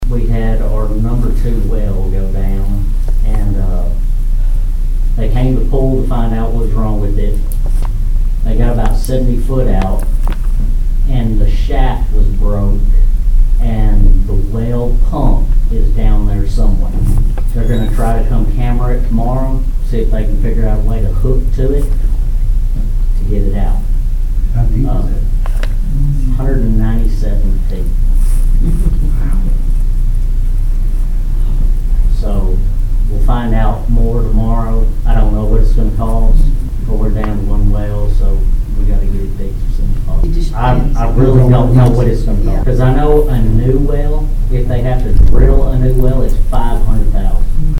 At last night’s Greenfield City Board Meeting, the board was presented with a broken well which could end up costing the city, $500,000.